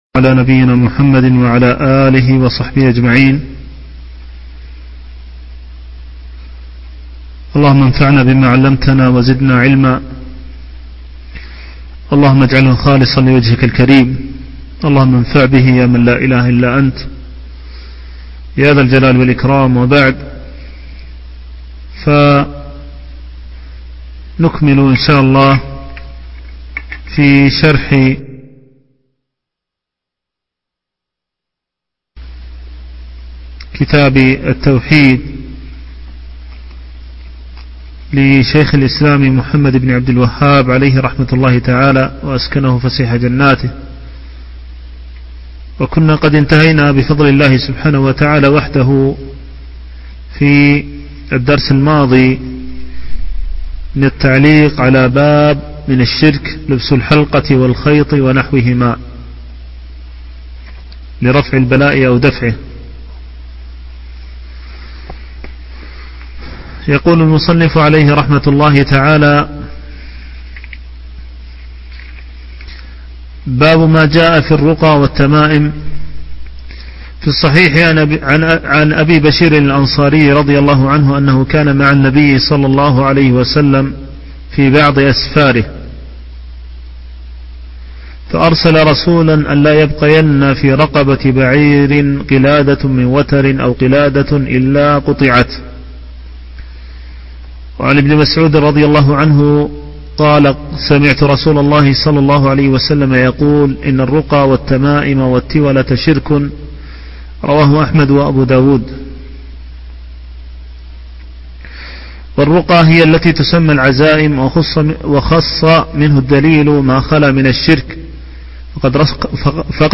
شرح كتاب التوحيد - الدرس التاسع